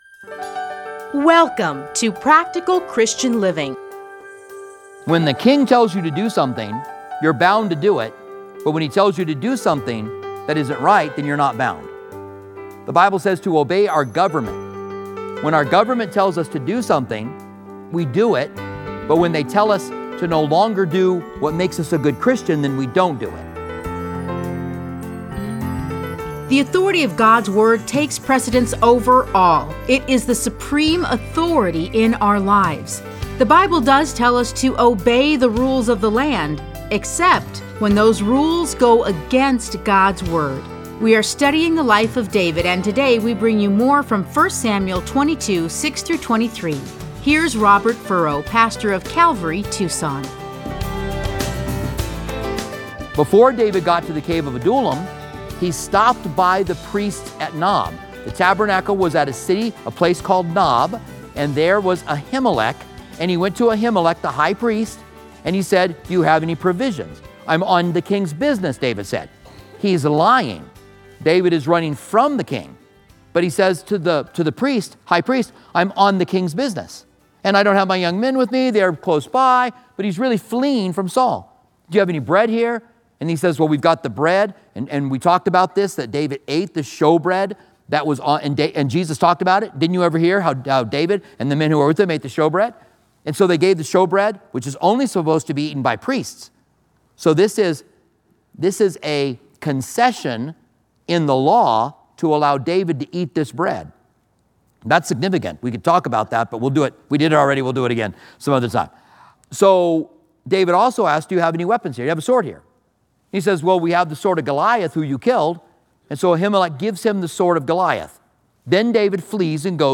Listen to a teaching from 1 Samuel 22:6-23.